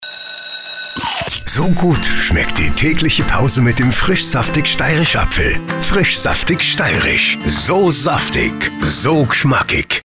Hörfunkspot, gebrandeter Bus und Out of Home-Aktivitäten.